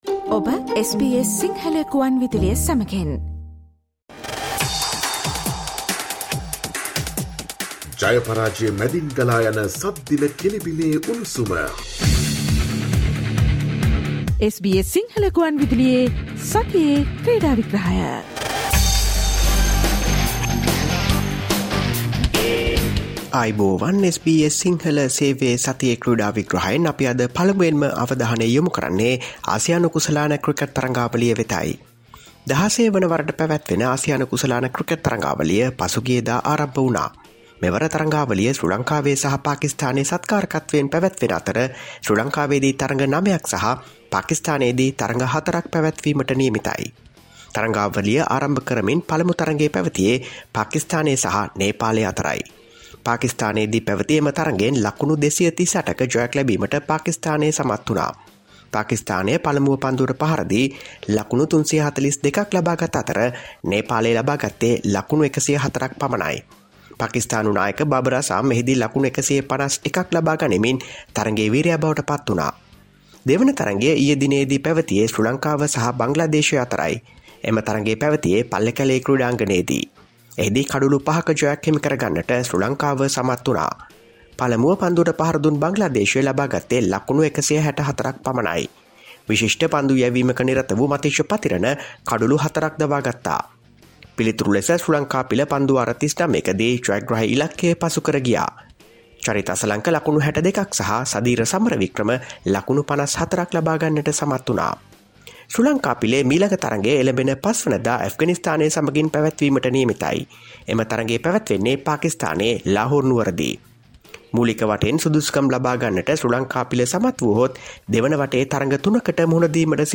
Listen to the SBS Sinhala Radio weekly sports highlights every Friday from 11 am onwards